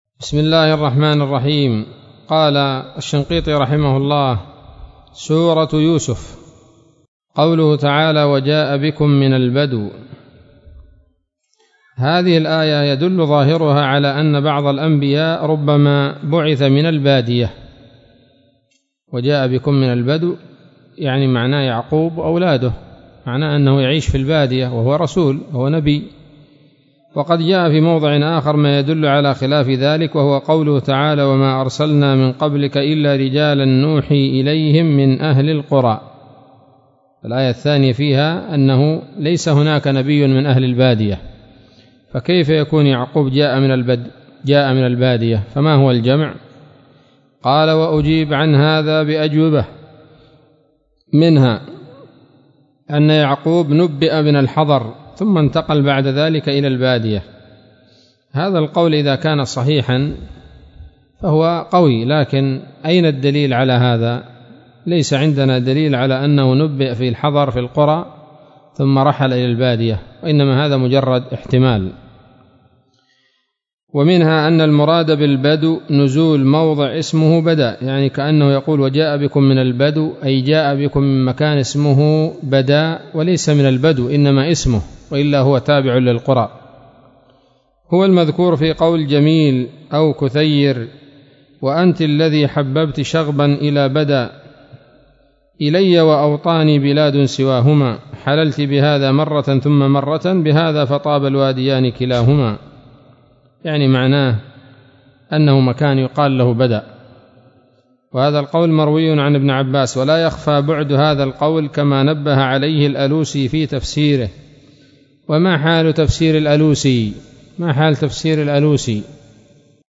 الدرس الحادي والخمسون من دفع إيهام الاضطراب عن آيات الكتاب